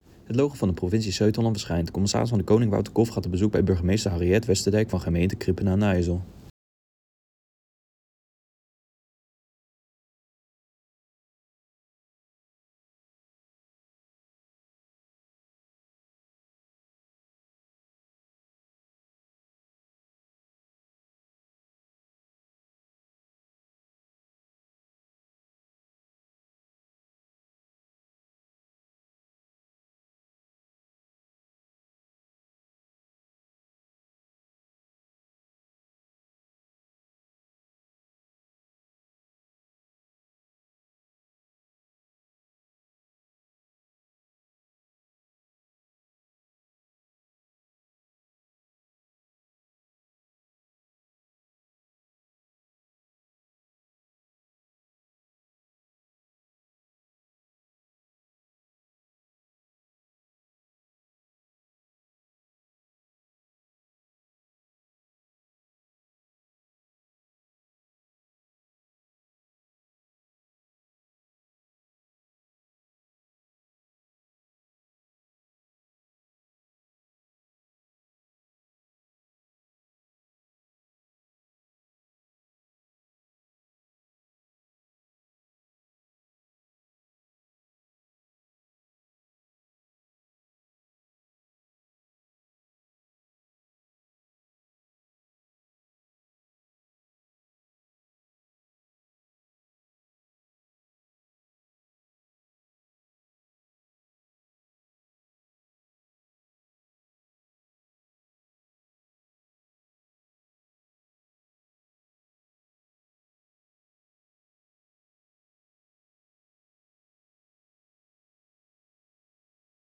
Commissaris van de Koning Wouter Kolff in gesprek met de burgemeester van Krimpen aan den IJssel, Harriët Westerdijk.